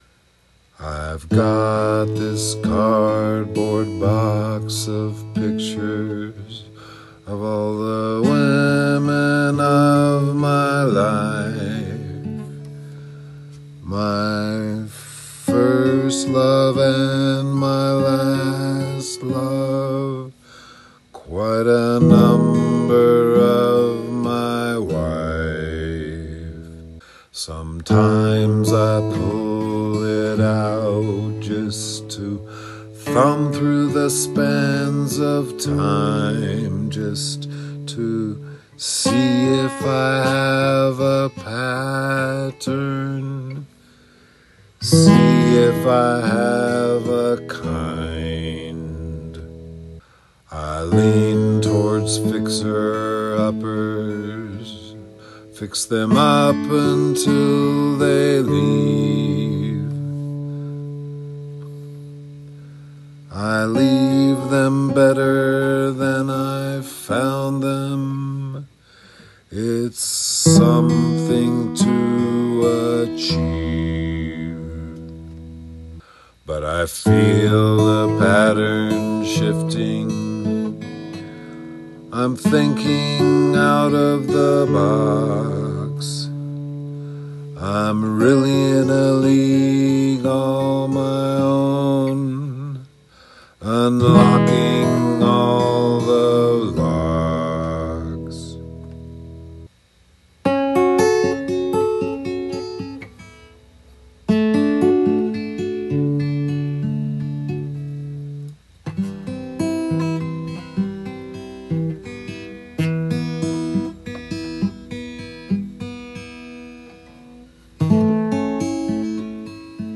I also got an F sharp minor in there.